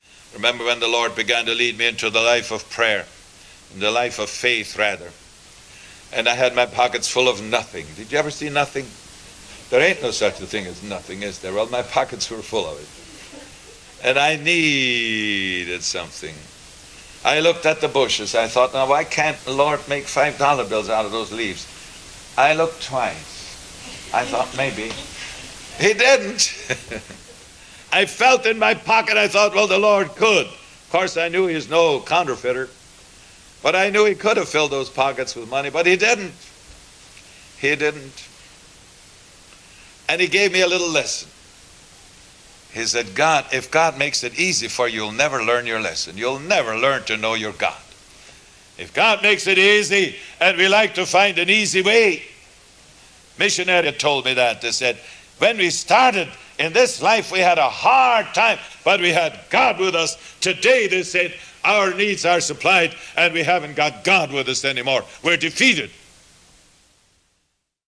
Audio Quality: Mixed